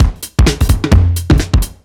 OTG_Kit 5_HeavySwing_130-C.wav